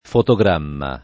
fotogramma [ foto g r # mma ] s. m.; pl. ‑mi